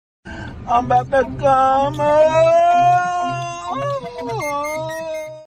Thể loại: Câu nói Viral Việt Nam
am-thanh-meme-an-ba-to-com-ban-goc-www_tiengdong_com.mp3